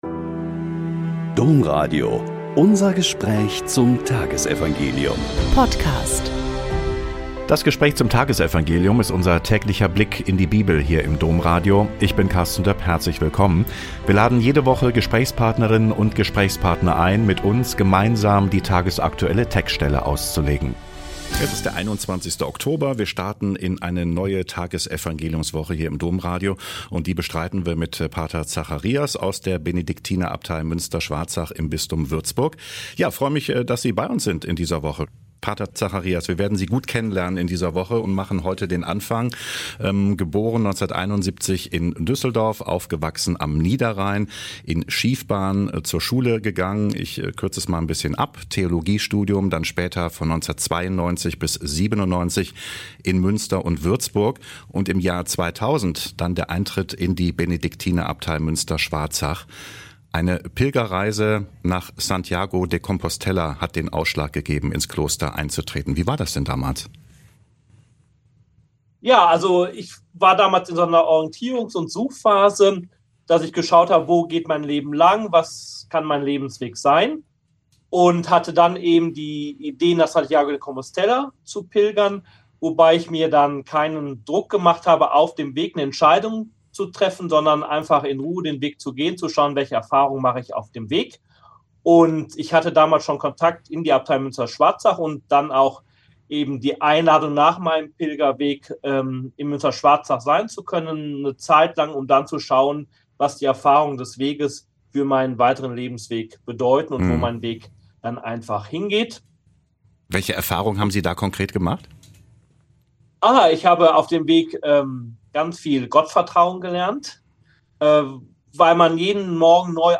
Lk 12,13-21 - Gespräch